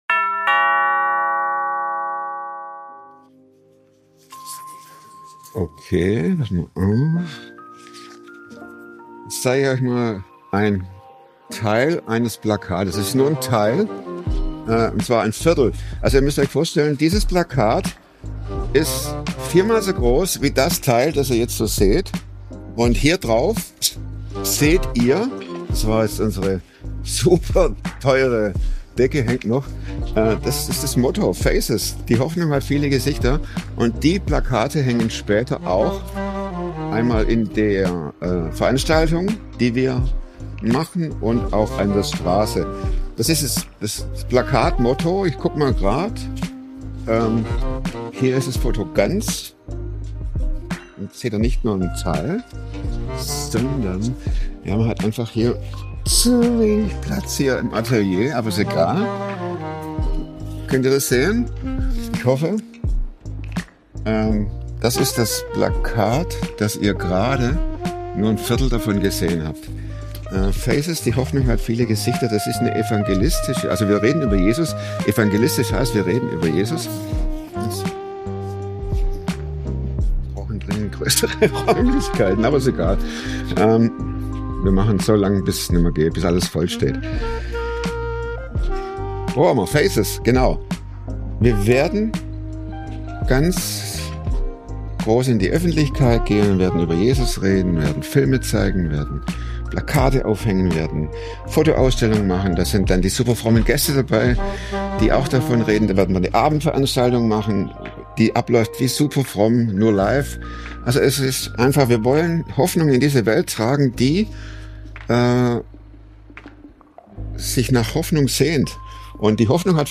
superfromm kommt wöchentlich. superfromm ist ein Mix aus Drama, Comedy und Alltagszoff. Vor dem Mikro ist im Atelier. Hier hocken Alltagshelden.